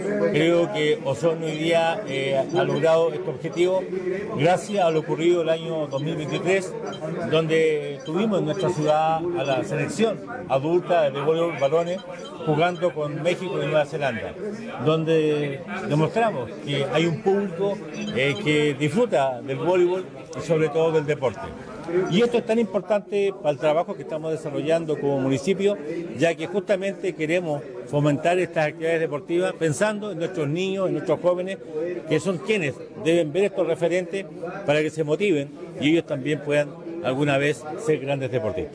El jefe comunal añadió que gracias al convenio que existe con la Federación Nacional de Voleibol y la exitosa realización del encuentro internacional previo a los Juegos Panamericanos, permitieron que la comuna sea considerada para albergar esta instancia internacional.